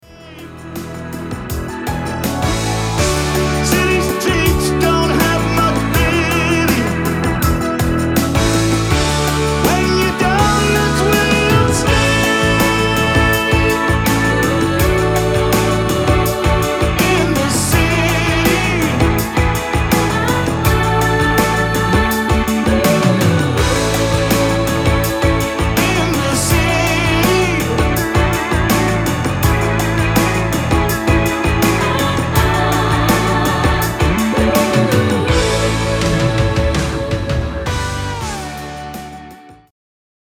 CLASSIC SONG COVERS
Backing Vocals…
Slide Guitar
Drum samples
Guitars, Keyboards and Drum programming
‘63 Fender Precision Bass
Mixed at Blue Mist Audio.